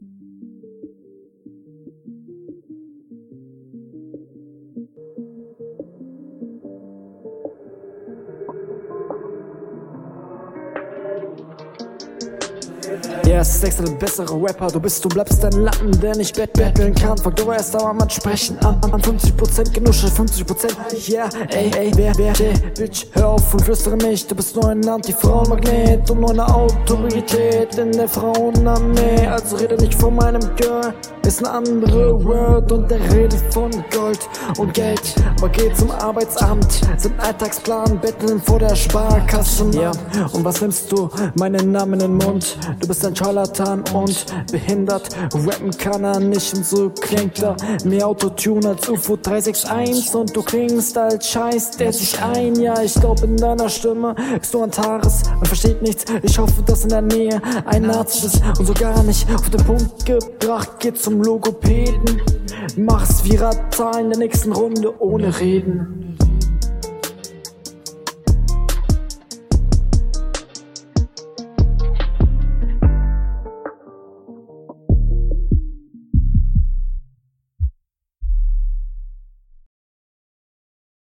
Was mir direkt aufgefallen ist war dein soundbild was mir gar nicht gefallen hat.....